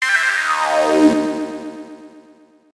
メール音やSMSの通知音。